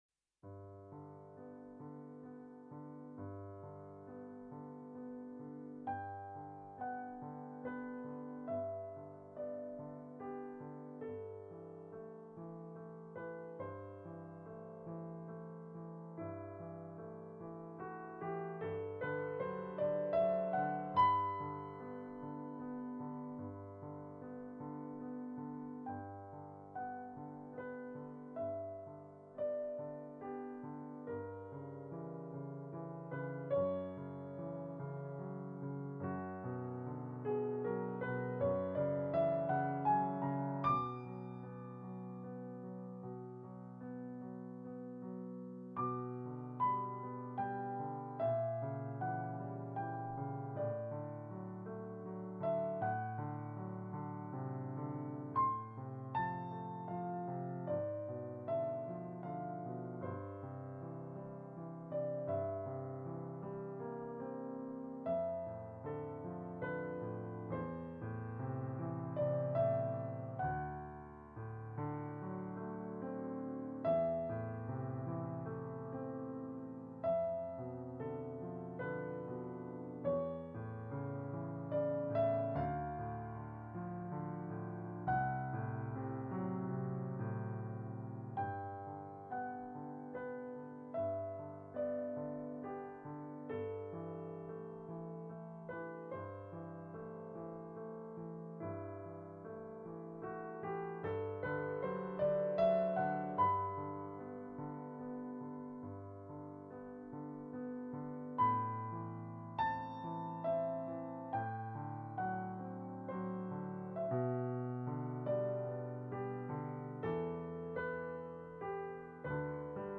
Musique : Camille Saint-Saêns - Le Carnaval des Animaux Le cygne piano solo (1886) - 1,82 Mo - 2 mn 25 :